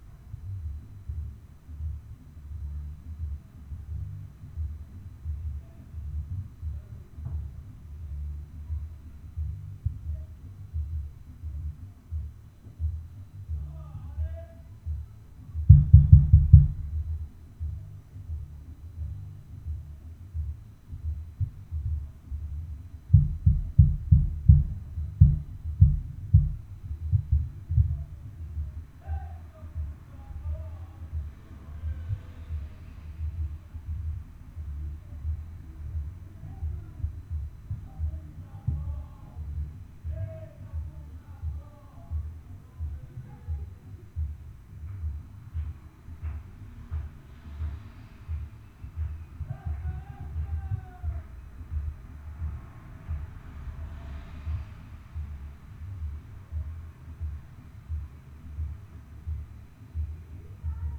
Es ist immer noch nicht so schlimm, wie es mal vor drei Jahren war, trotzdem hört man deutlich ein verzerrtes Summen aus den Wänden.
Und das auf eine völlig verzerrte Art, man weiß nicht gerade, was für eine Musikrichtung eingeschaltet wurde.